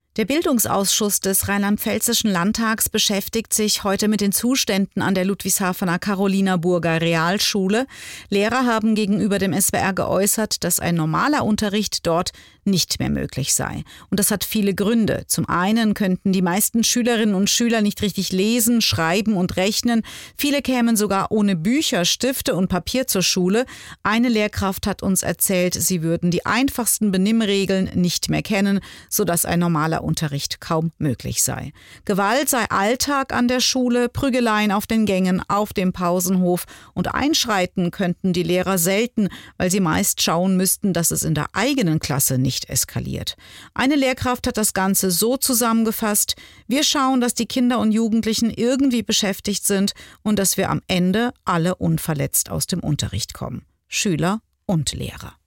Die Angst vor Disziplinarverfahren oder internen Repressionen ist zu groß, wie sie im Interview erklärt.